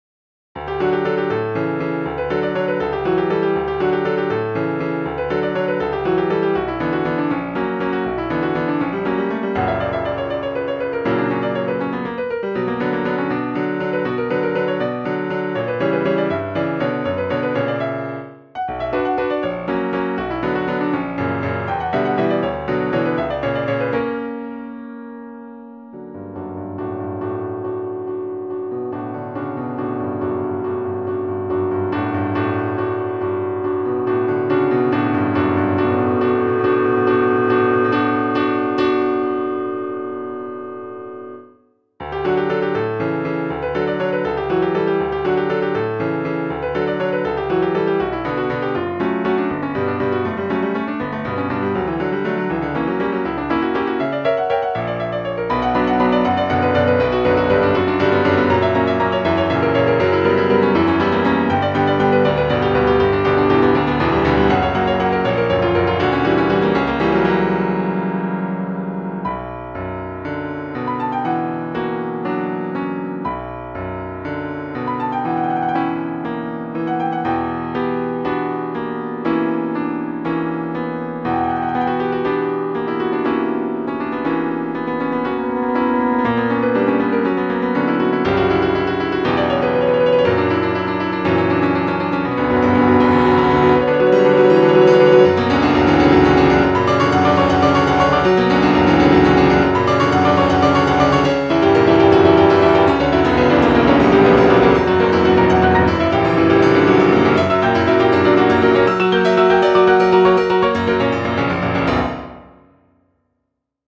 Etude no.2 - Piano Music, Solo Keyboard - Young Composers Music Forum